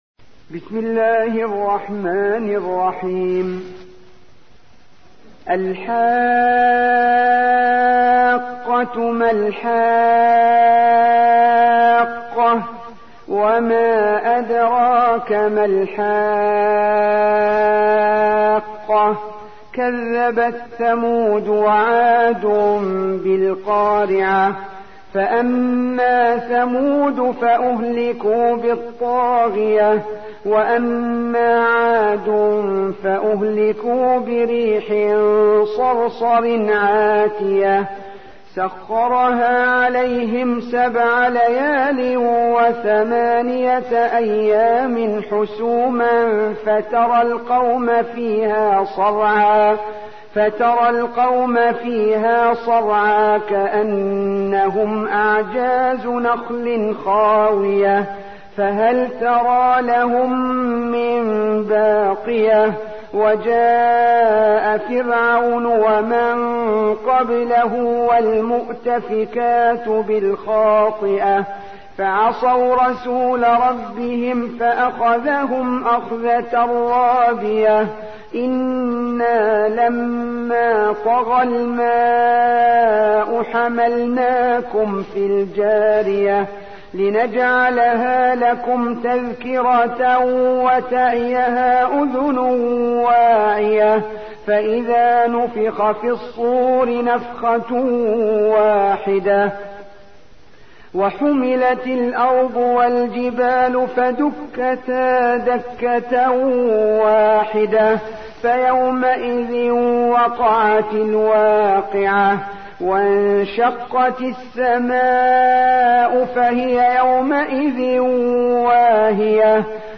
69. سورة الحاقة / القارئ